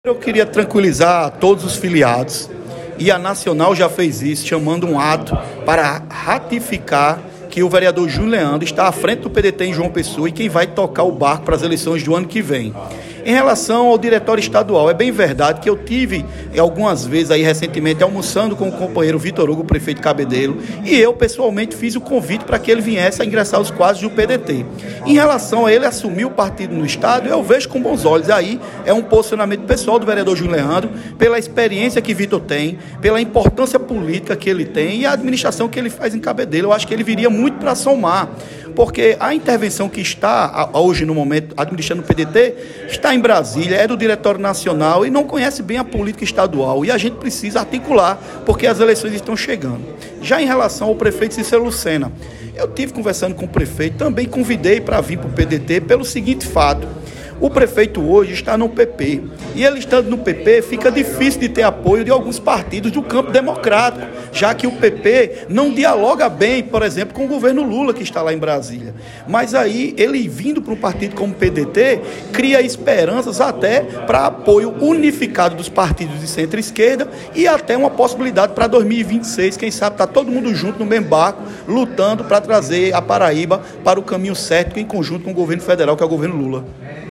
Abaixo a fala do vereador de João Pessoa, Junio Leandro, a reportagem do Portal PautaPB.